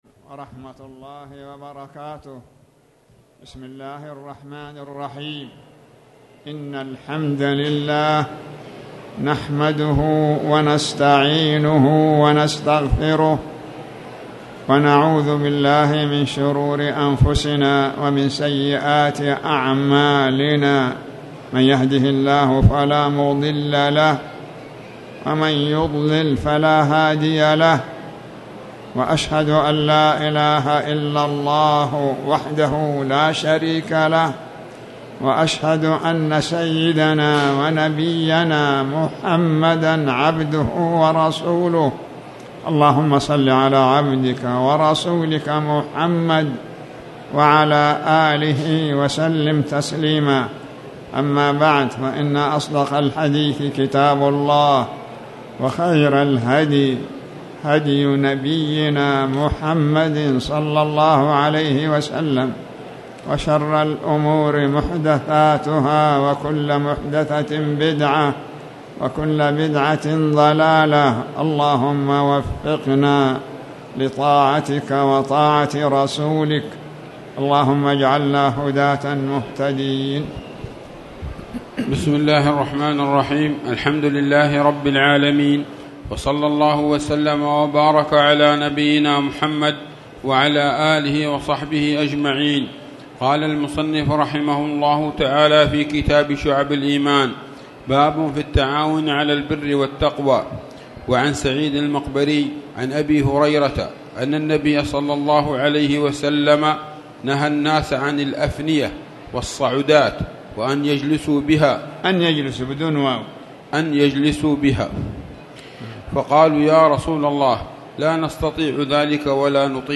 تاريخ النشر ١٧ شوال ١٤٣٨ هـ المكان: المسجد الحرام الشيخ